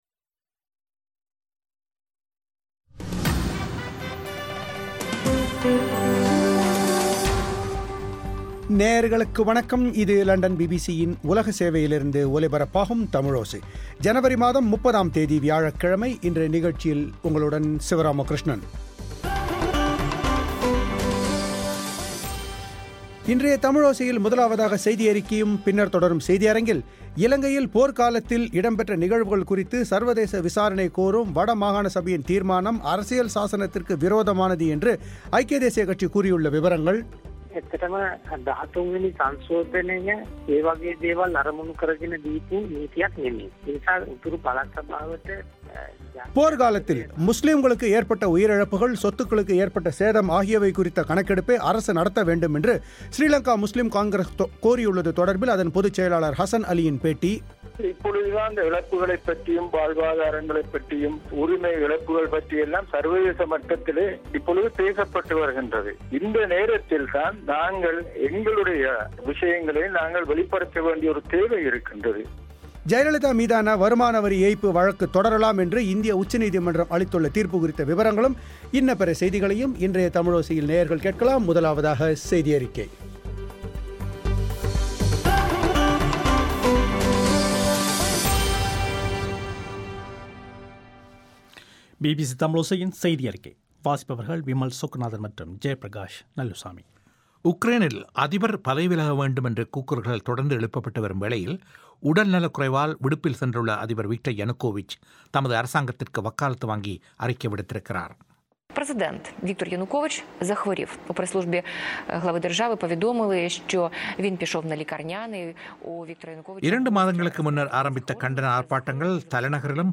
போர் காலத்தில் முஸ்லிம்களுக்கு ஏற்பட்ட உயிரிழப்புகள், சொத்துக்களுக்கு ஏற்பட்ட சேதம் ஆகியவை குறித்த கணக்கெடுப்பை அரசு நடத்த வேண்டும் என ஸ்ரீலங்கா முஸ்லிம் காங்கிரஸ் கோரியுள்ளது தொடர்பில் அதன் பொதுச் செயலர் ஹஸன் அலியின் பேட்டி